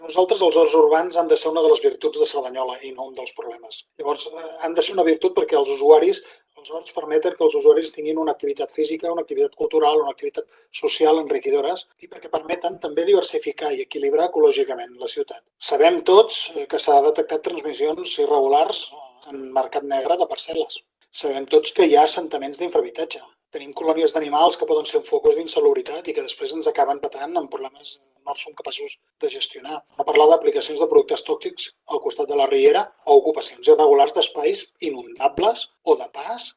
El regidor d’ERC,